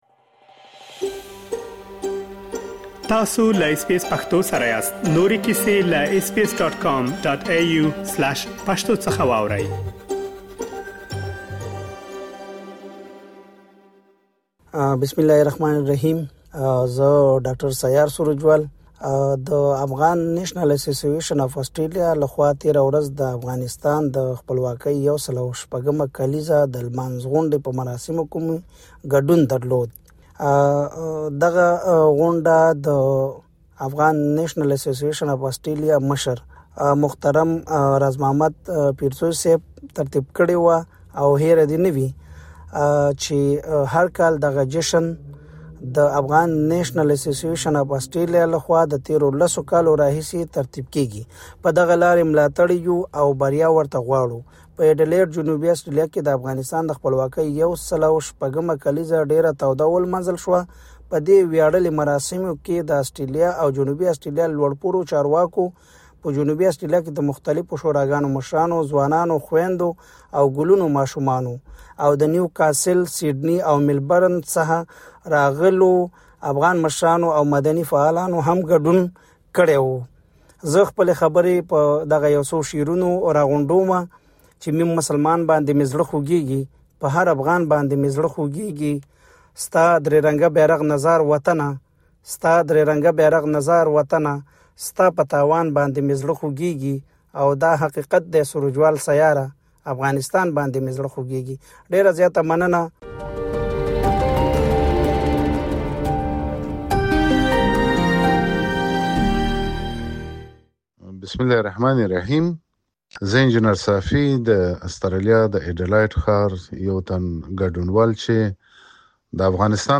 د آسټرالیا په اډېلېډ ښار کې مېشتو افغانانو د افغانستان د خپلواکۍ ۱۰۶مه کالیزه ونمانځله. د خپلواکۍ په مناسبت د جوړې شوې غونډې یو شمېر ګډونوالو له اس بي اس پښتو سره خپل معلومات او نظرونه شریک کړي.